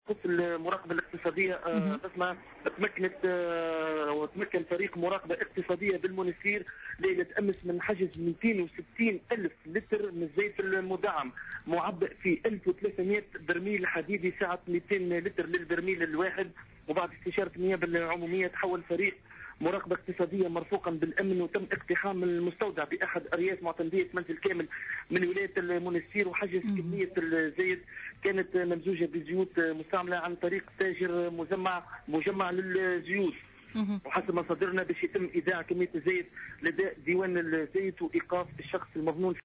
اكثر تفاصيل مع مراسلنا